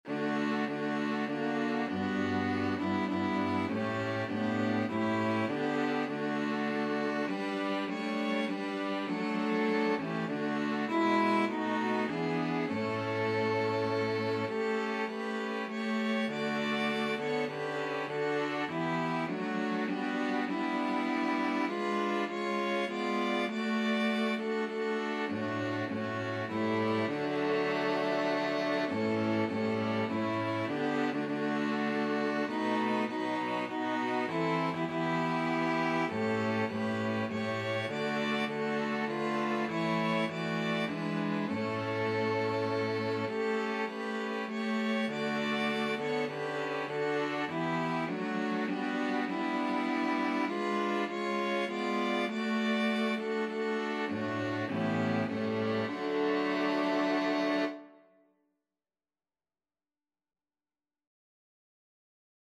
Violin 1Violin 2ViolaCello
3/4 (View more 3/4 Music)